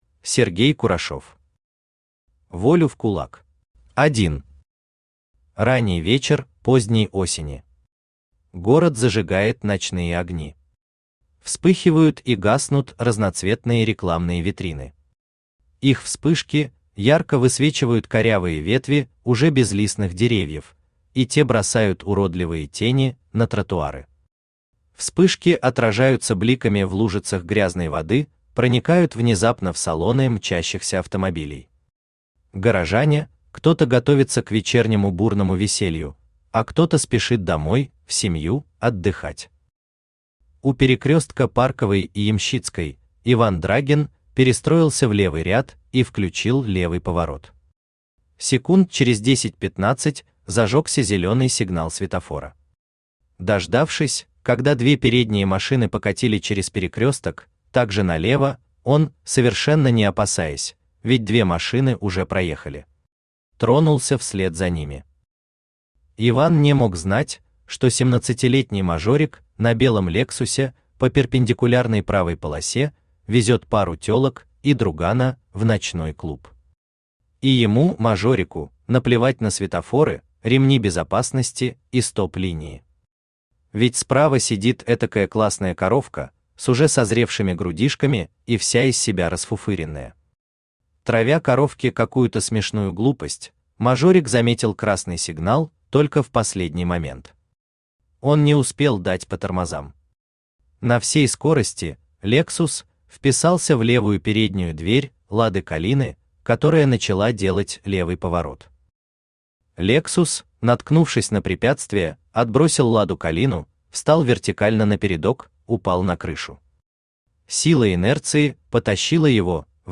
Аудиокнига Волю в кулак!
Автор Сергей Павлович Курашов Читает аудиокнигу Авточтец ЛитРес.